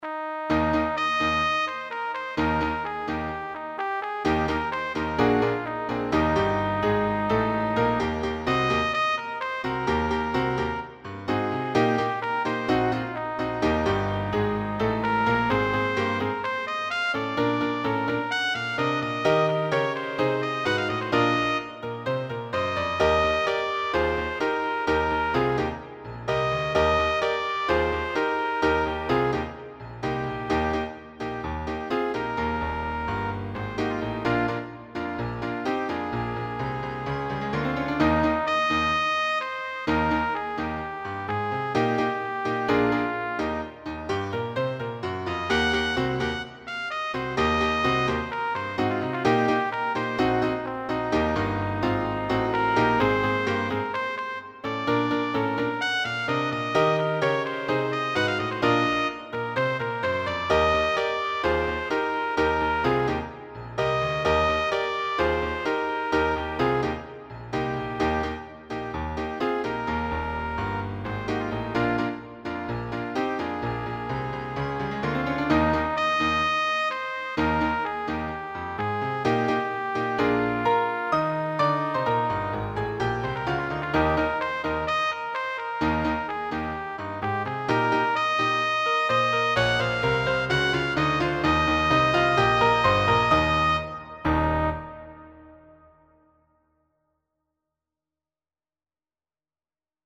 rockin’ arrangement
for Trumpet or Trombone solo with piano accompaniment.
Rock and Pop